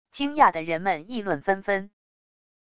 惊讶的人们议论纷纷wav音频